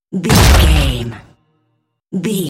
Dramatic hit drum metal debris
Sound Effects
Atonal
heavy
intense
dark
aggressive
hits